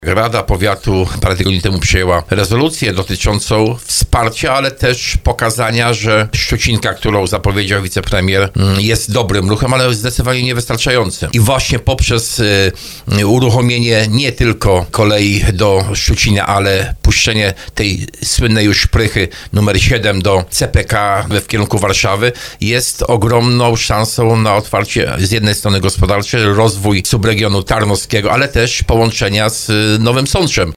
Połączenie kolejowe powinno powstać nie tylko do Szczucina, ale dalej, do Buska – Zdroju– mówił starosta tarnowski Jacek Hudyma w audycji Słowo za Słowo na antenie Radia RDN Małopolska.